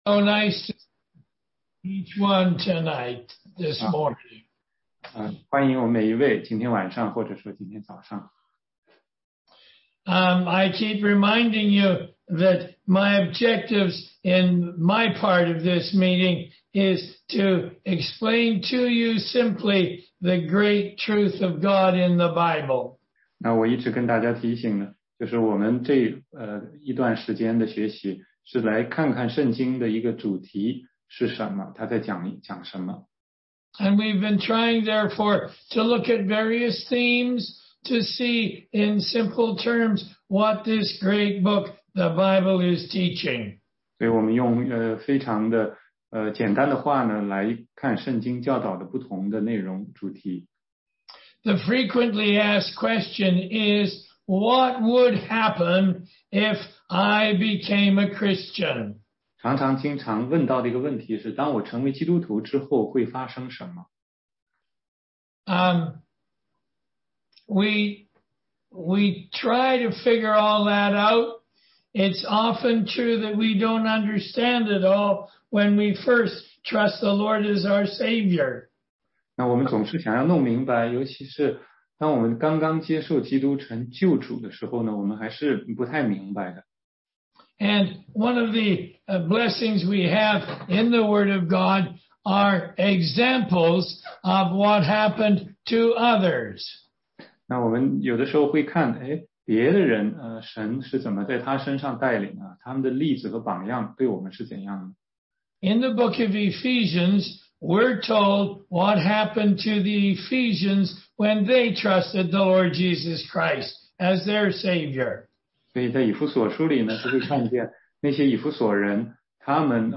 16街讲道录音 - 福音课第三十七讲